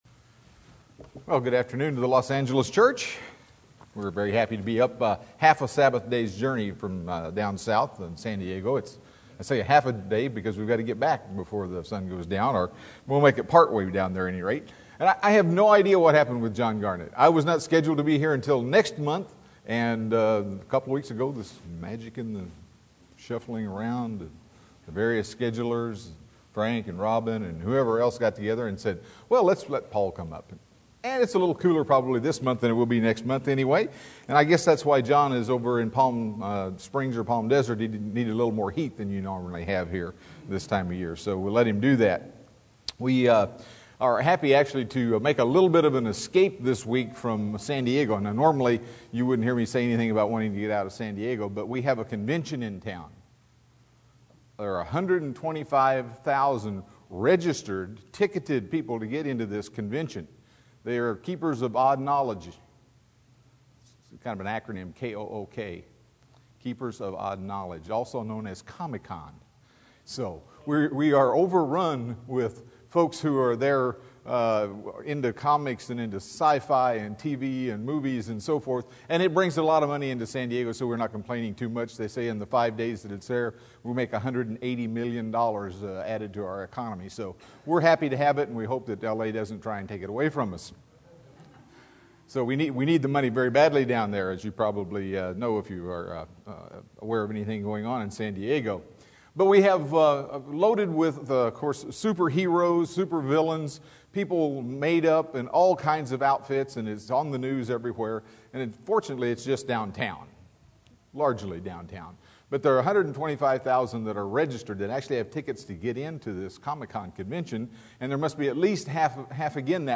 UCG Sermon Studying the bible?
Given in Los Angeles, CA